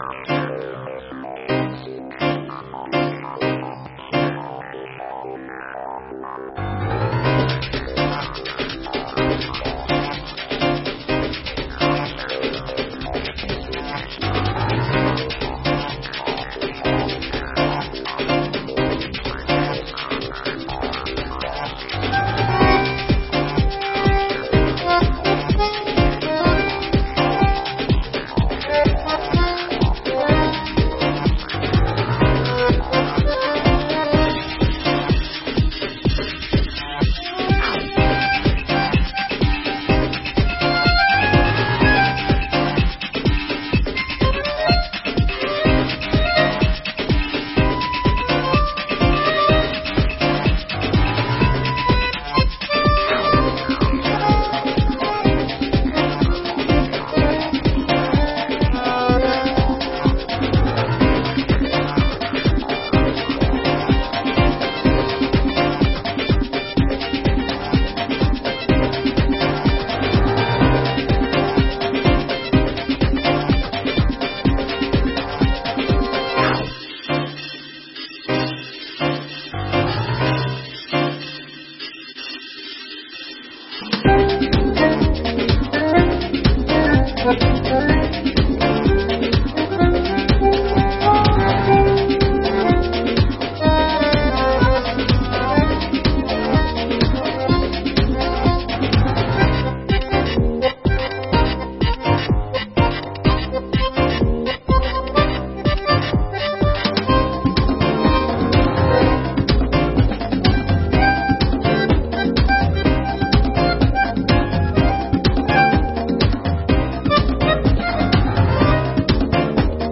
visitó los estudios de Radio Universidad 93.5 y Provincia 23 y expuso el profundo malestar generado por la rotura de la caldera, que impidió la faena de 15 animales que había dejado este fin de semana.